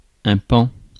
Ääntäminen
Ääntäminen France: IPA: /pɑ̃/ Haettu sana löytyi näillä lähdekielillä: ranska Käännös 1. паун {m} (paún) Suku: m .